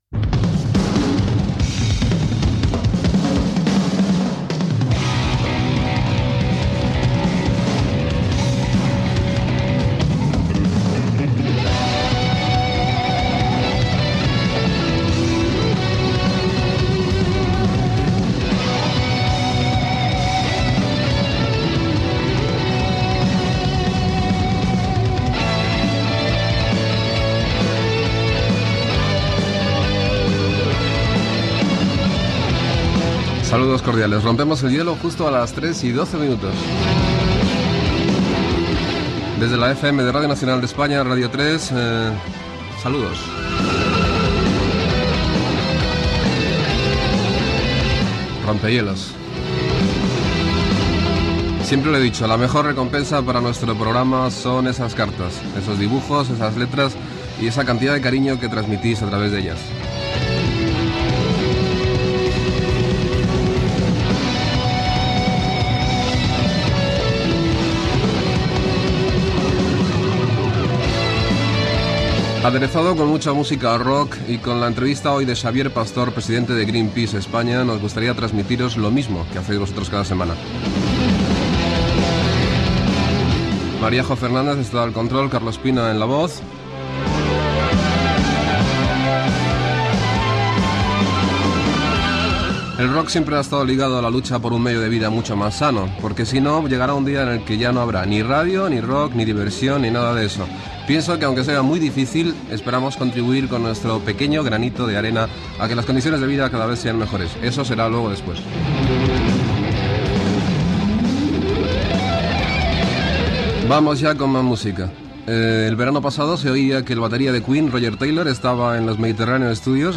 Sintonia, hora, identificació, presentació i tema musical